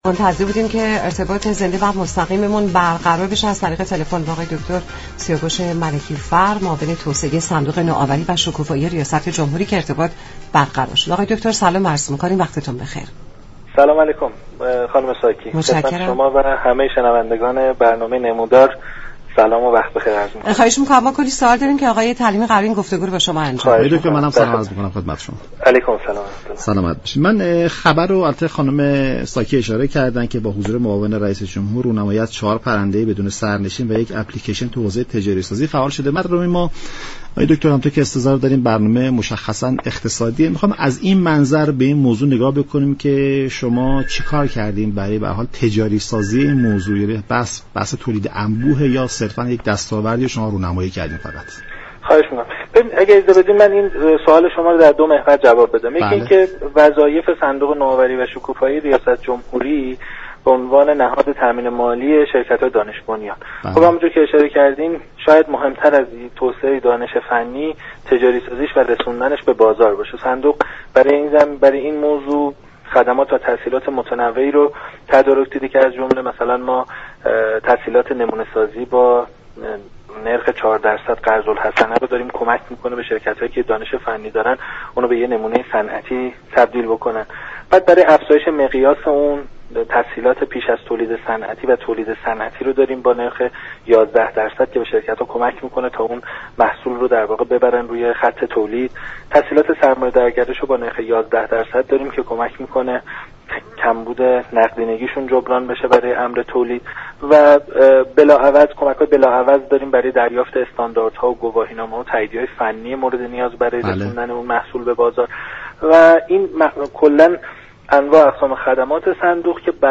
به گزارش شبكه رادیویی ایران، «سیاوش ملكی فر» معاون توسعه صندوق نوآوری و شكوفایی ریاست جمهوری در گفت و گو با برنامه «نمودار» از رونمایی چهار پرنده بدون سرنشین و تجاری سازی آنها خبر داد و گفت: صندوق نوآوری و شكوفایی ریاست جمهوری برای تجاری سازی این محصول خدمات و تسهیلات متنوع را تدارك دیده است، اختصاص تسهیلات نمونه سازی با نرخ 4 درصد به شركت ها و ارائه كمك های بلاعوض برای دریافت استانداردها و گواهینامه های فنی در زمره این موارد هستند.